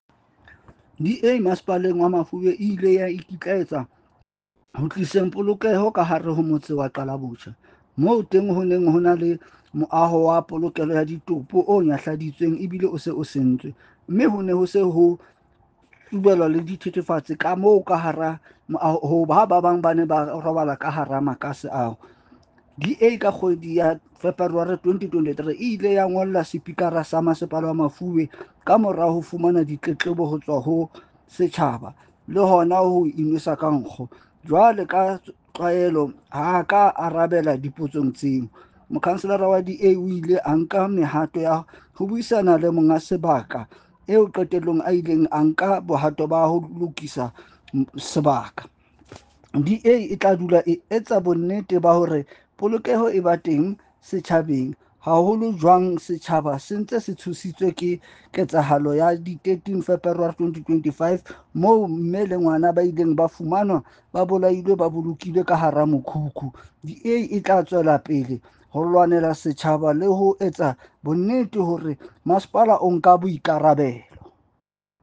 Sesotho soundbites by Cllr Fako Tsotetsi.